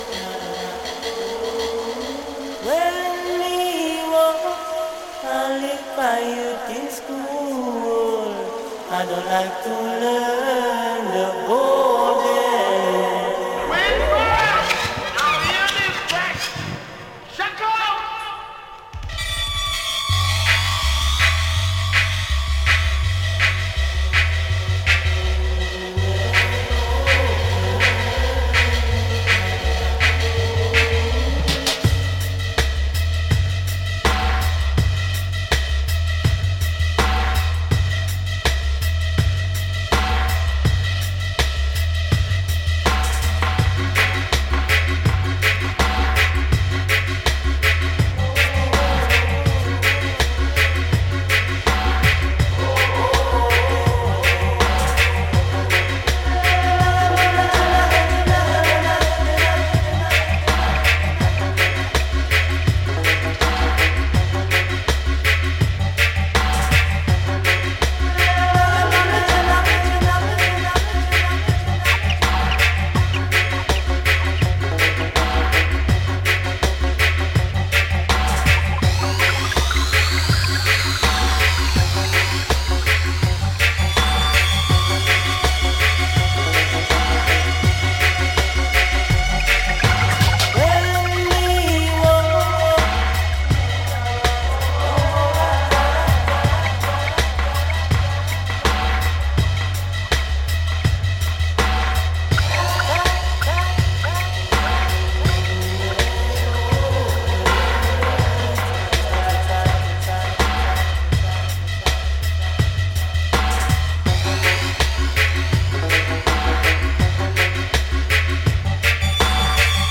ダブヴァージョンの飛び加減、ヘヴィネスも万全。